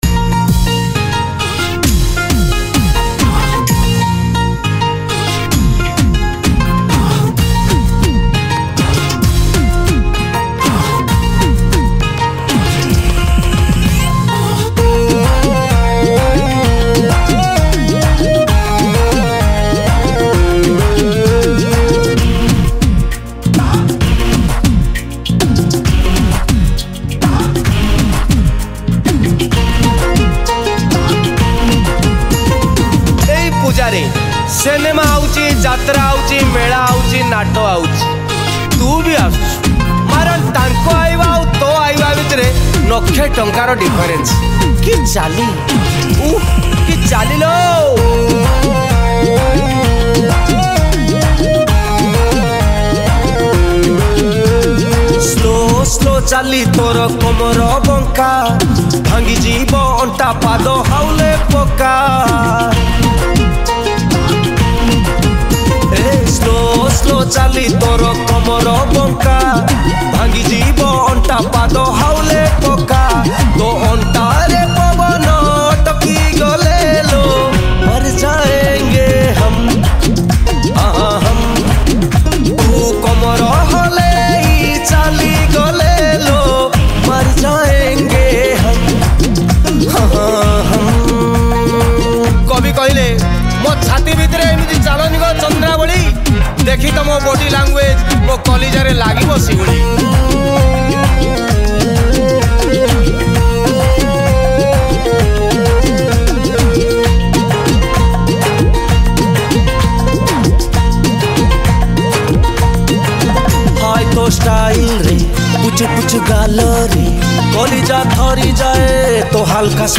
New Odia Comedy Song